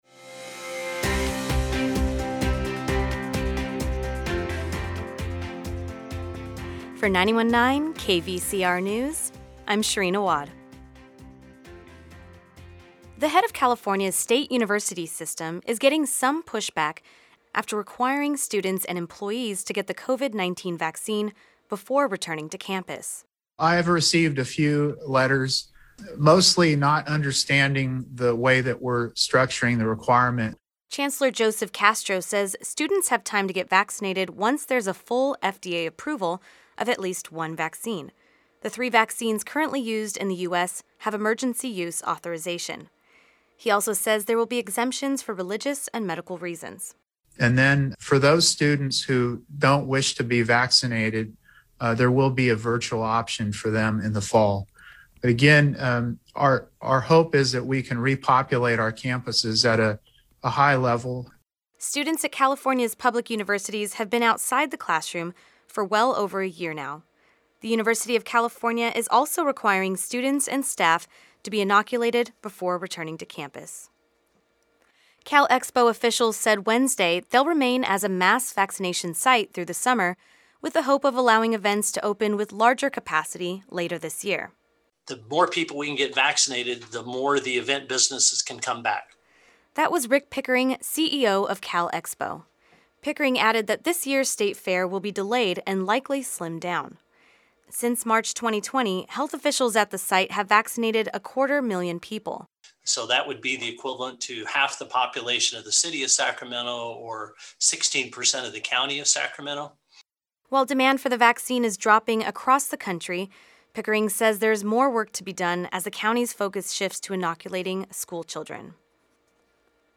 KVCR News has your daily news rundown at lunchtime.